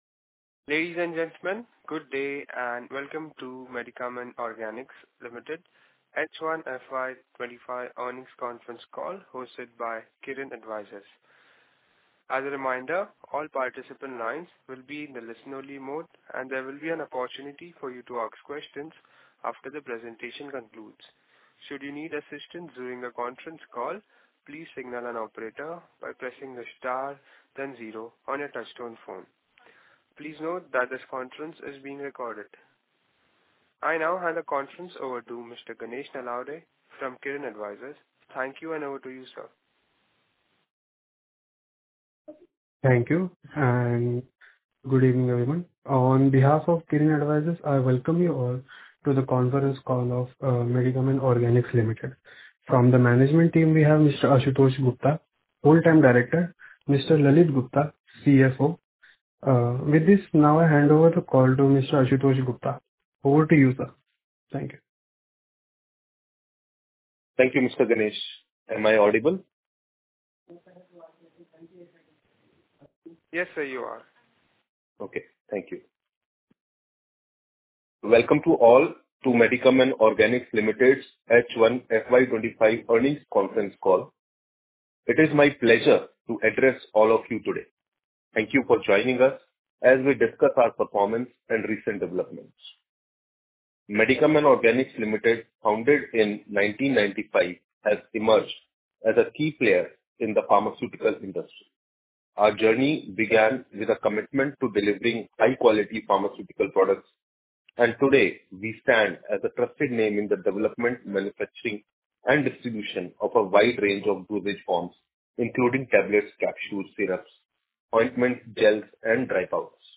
H1_FY25_Concall_Audio_Recording.mp3